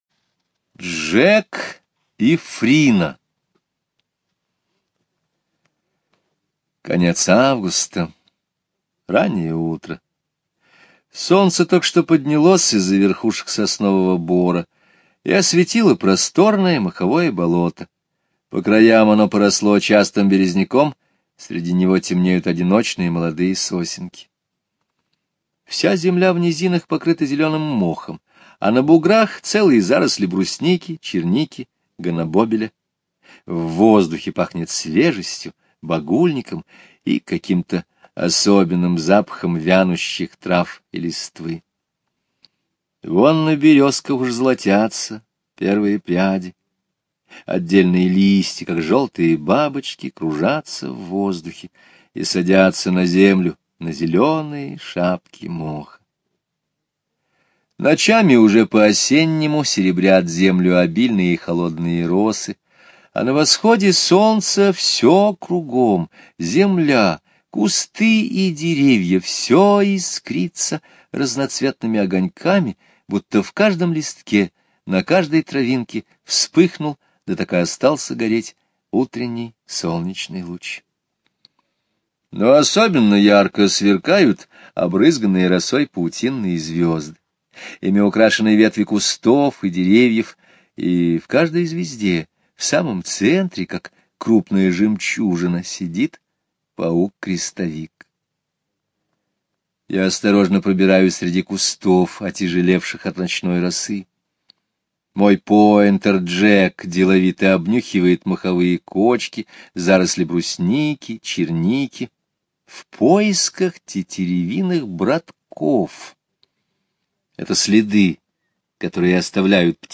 Слушайте Джек и Фрина - аудио рассказ Скребицкого Г. Рассказ о том, как автор охотится на тетеревов с собакой Джеком.